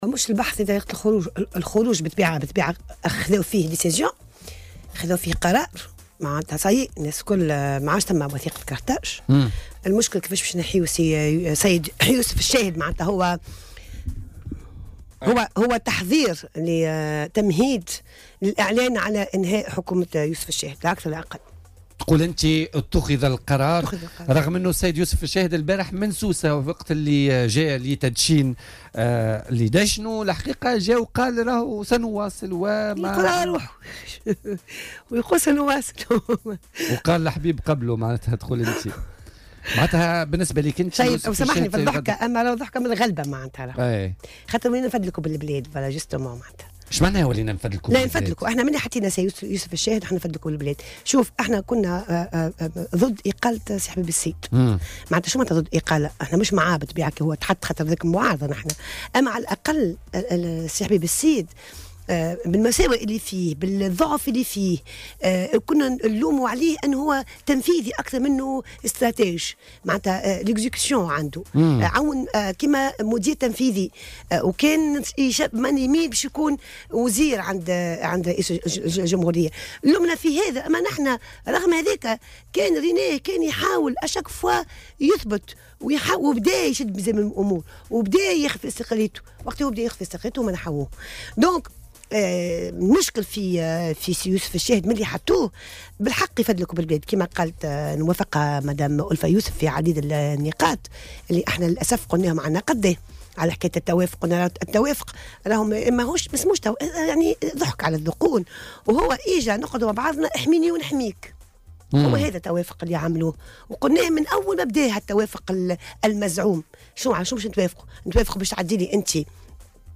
وأكدت ضيفة "بوليتيكا" على "الجوهرة أف أم" أنه تم اتخاذ هذا القرار معتبرة أن الشاهد يفتقر لتجربة تؤهله بأن يكون رئيسا للحكومة، بحسب ترجيحها.